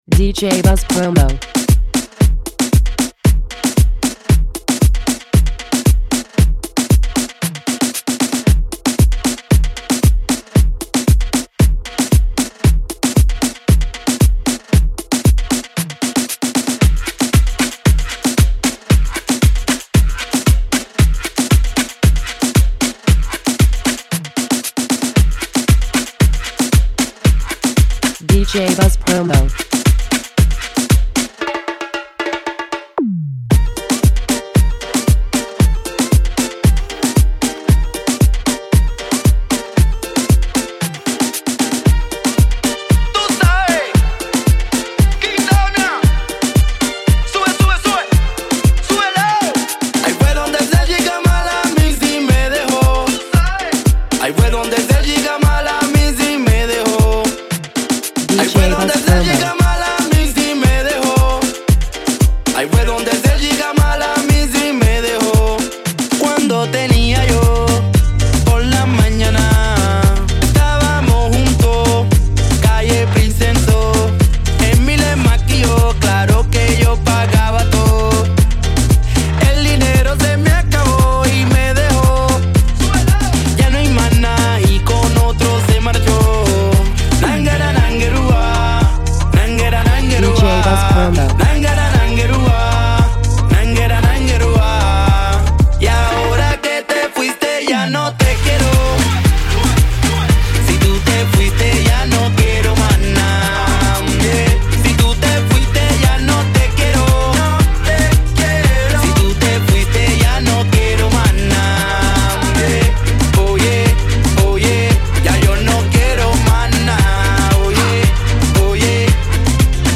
Latin version!
Extended Remix